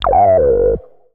T2_squelchbass
T2_squelchbass.wav